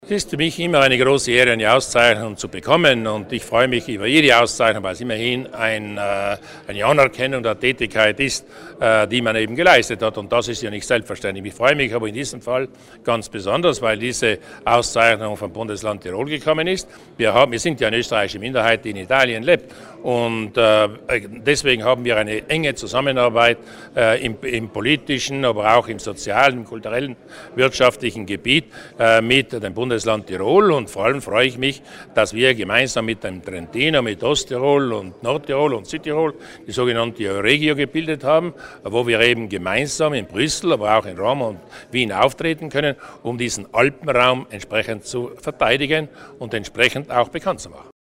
Landeshauptmann Durnwalder bedankt sich für die Tiroler Auszeichnung